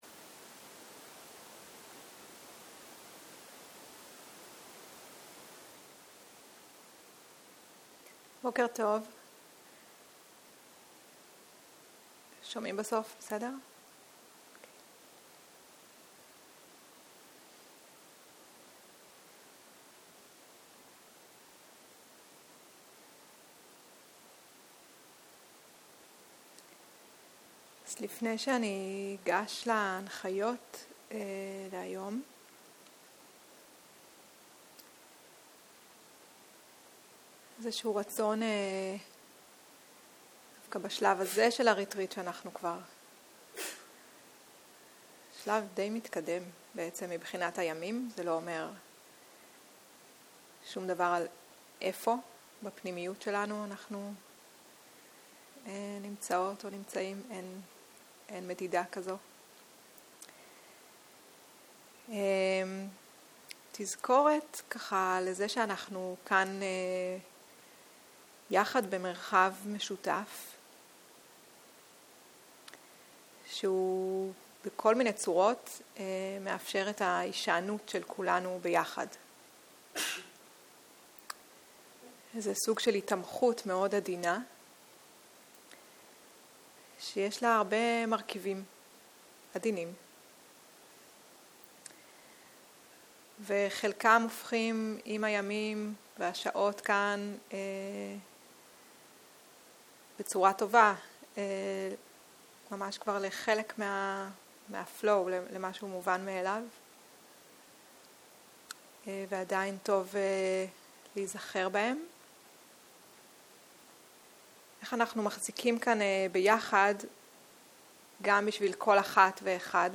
סוג ההקלטה: שיחת הנחיות למדיטציה